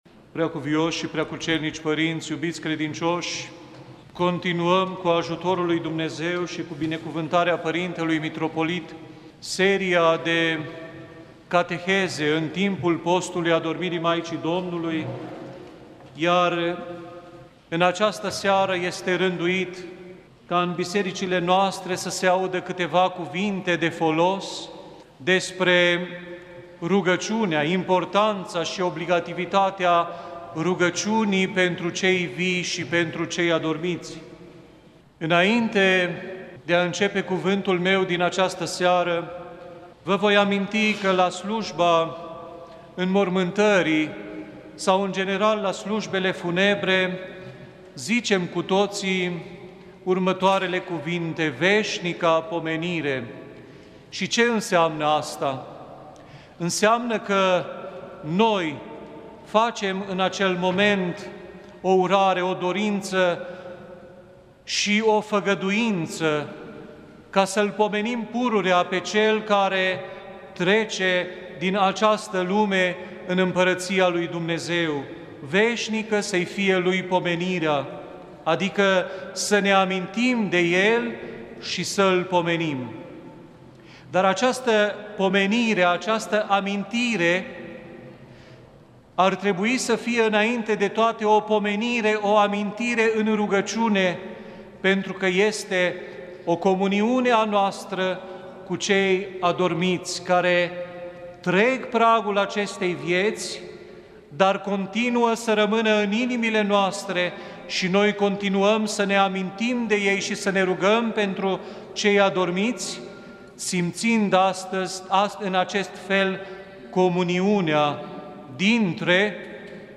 cateheză